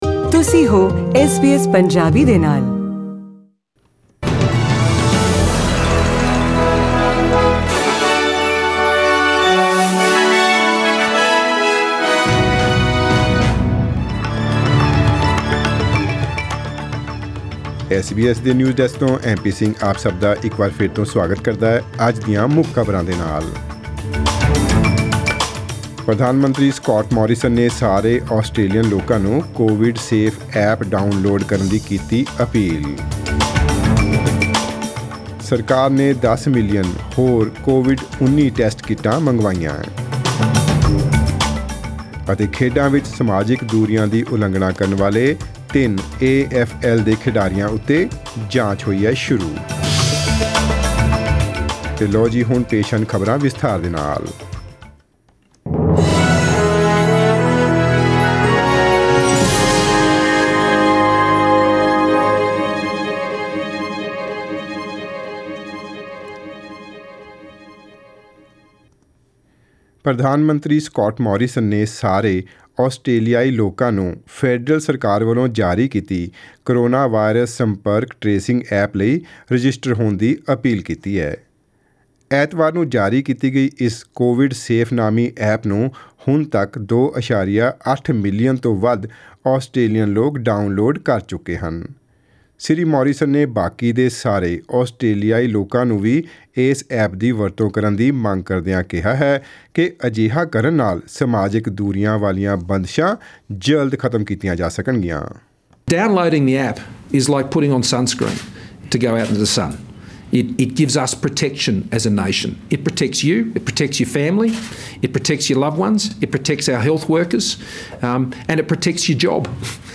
In today’s news bulletin: Australians urged to download to government's coronavirus contract tracing app; The government acquires 10 million additional COVID-19 tests; and in sport, Victoria police investigate the theft of an A-F-L premiership medal.